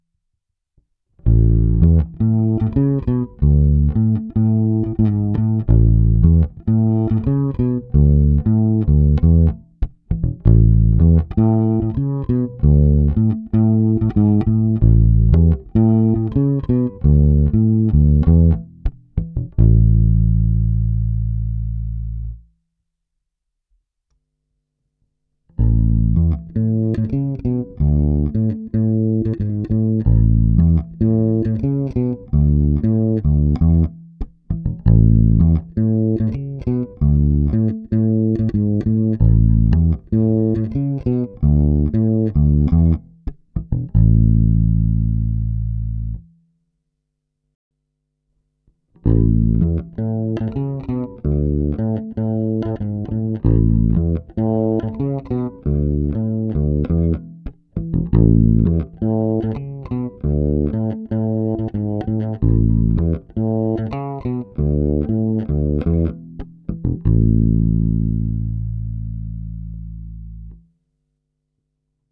Na oba snímače je zvuk trochu sterilní, ale to je dáno zmíněnou PJ konfigurací.
Nahrál jsem několik ukázek v pořadí krkový snímač, oba snímače, kobylkový snímač. Použité struny jsou notně hrané nylonové tapewound hlazenky Fender 9120. Nahráno je to vždy přímo do zvukové karty a nahrávky byly jen normalizovány, jinak ponechány bez dodatečných úprav.
Aktivní režim s přidanými basy a středy cca o 50%